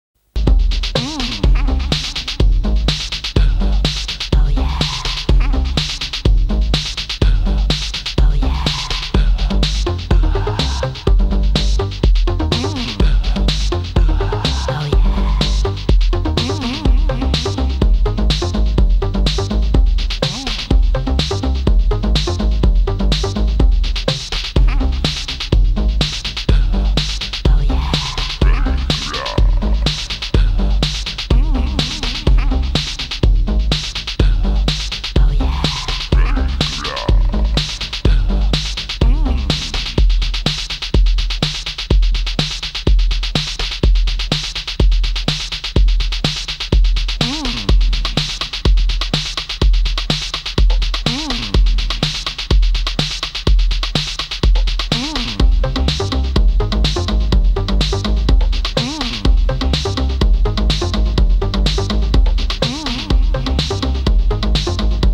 Raw and rocking Chicago-like 6 tracker..
House Techno